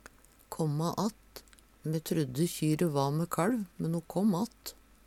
kåmmå att - Numedalsmål (en-US)